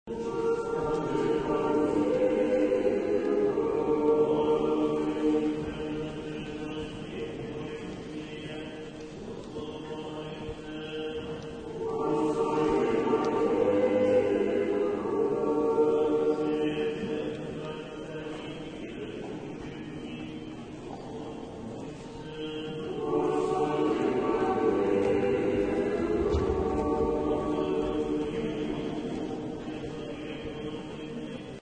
Inside, later in the ceremony, the priests open the doors.
However, you can't see from whom the singing comes from as the choir is out of view.
Clear as tears.